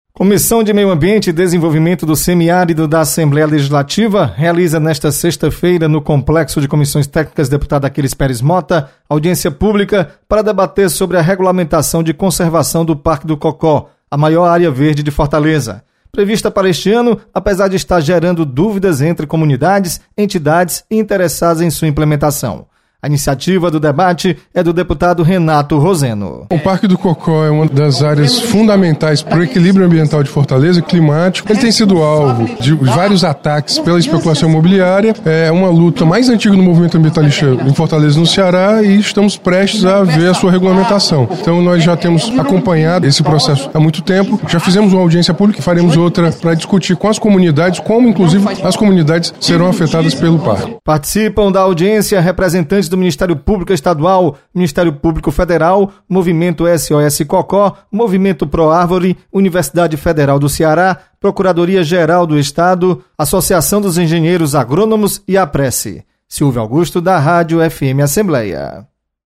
Você está aqui: Início Comunicação Rádio FM Assembleia Notícias Audiência